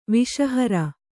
♪ viṣa hara